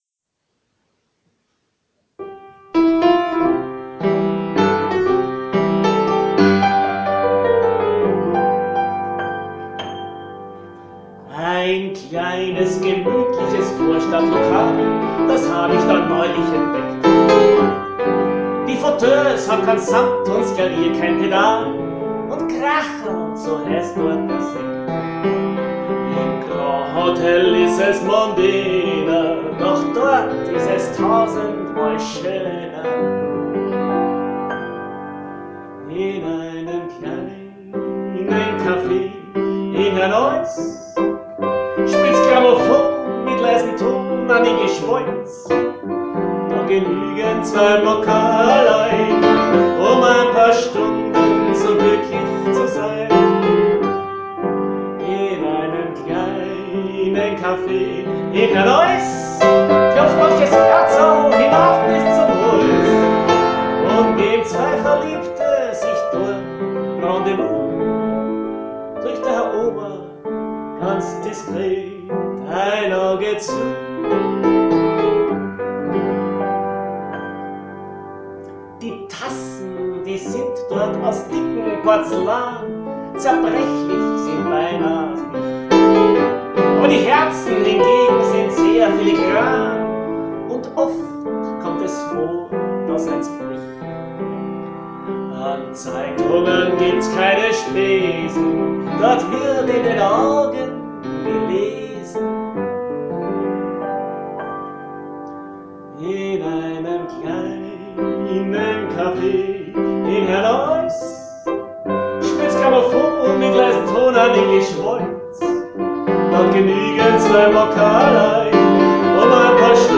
Des Glück is a Vogerl...Wienerlieder mit Qualität!
Musikprogramm mit Akkordeon und Gesang (und evt. E-Piano)